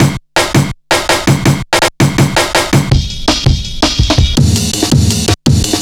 Index of /90_sSampleCDs/Zero-G - Total Drum Bass/Drumloops - 2/track 41 (165bpm)